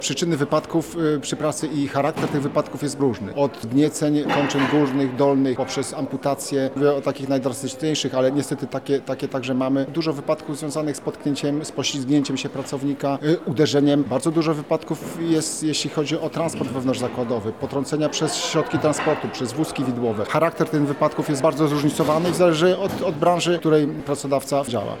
Podczas konferencji, eksperci i naukowcy wspólnie z Państwową Inspekcją Pracy analizowali metody zapobiegania tragediom w zakładach.